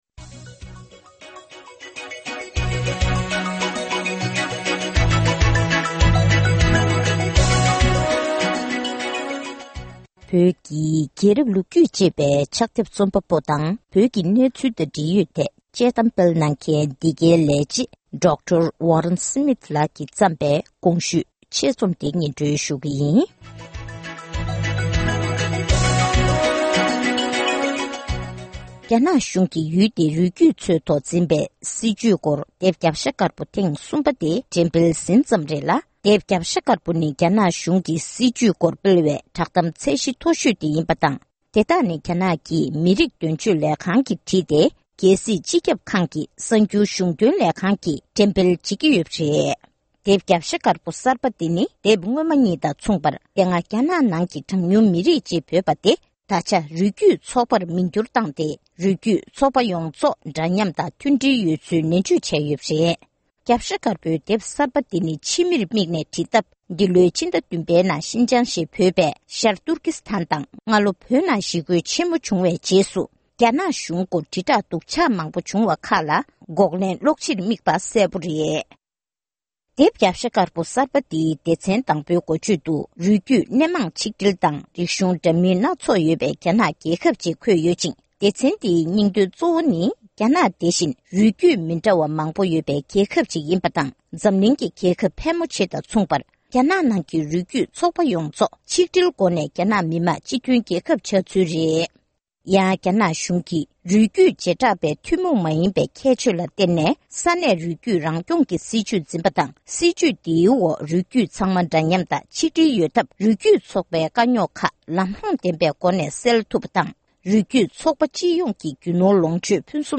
སྒྲ་ལྡན་གསར་འགྱུར། སྒྲ་ཕབ་ལེན།
བོད་སྐད་ཐོག་ཕབ་བསྒྱུར་གྱིས་སྙན་སྒྲོན་ཞུས་པར་གསན་རོགས༎